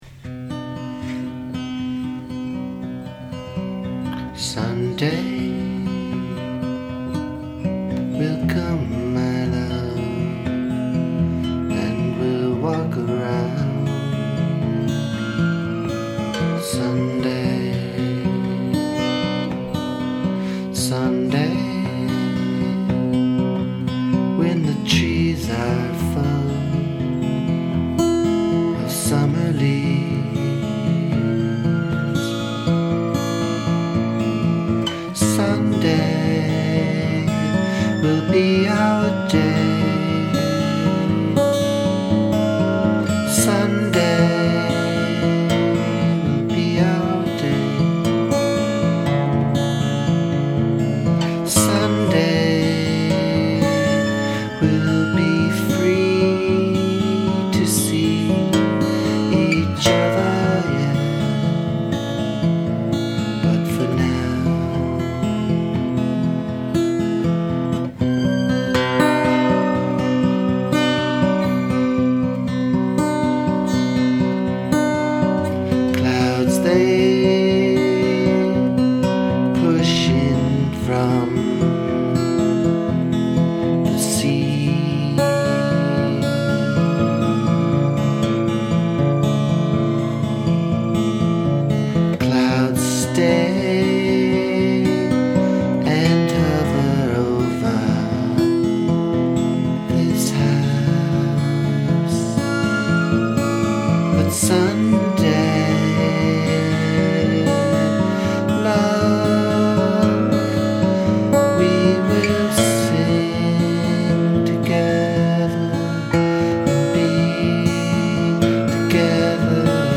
Tags: music folk pop original songs